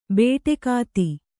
♪ bēṭekāti